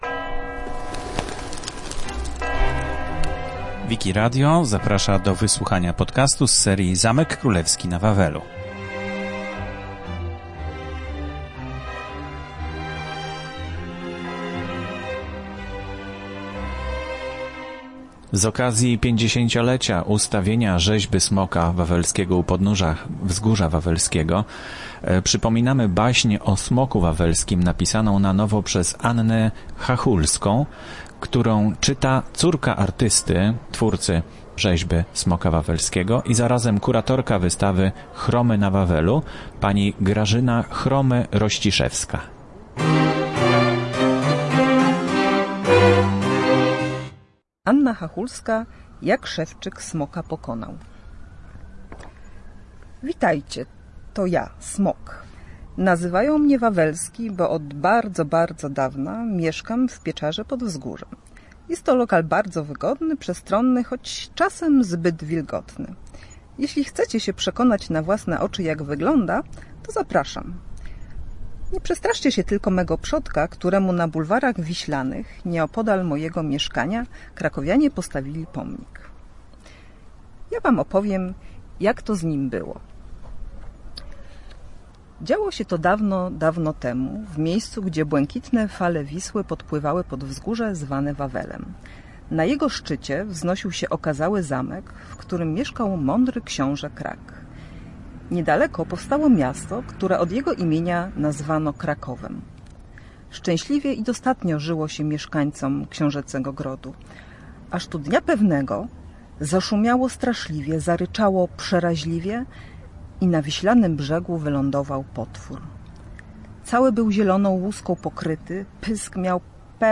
czyta na nowo napisaną przez Annę Chachulską legendę o Smoku Wawelskim.